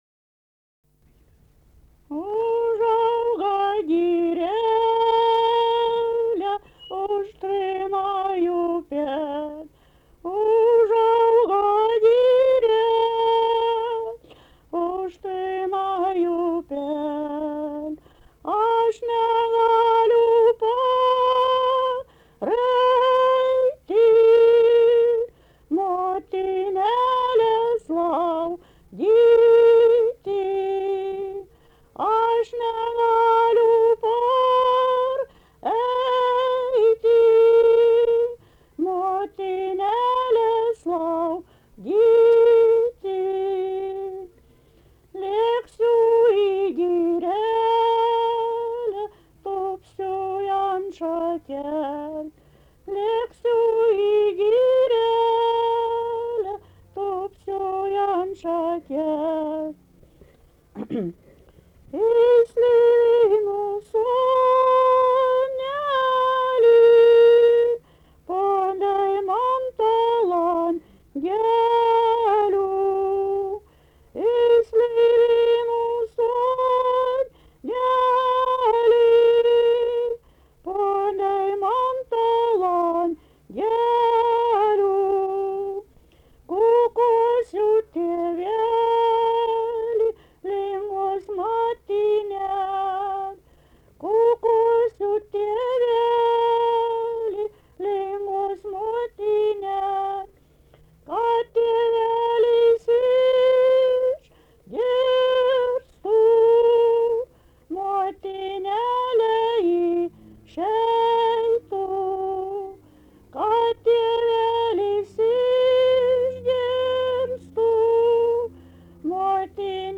daina
Genaičiai
vokalinis